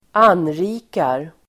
Uttal: [²'an:ri:kar]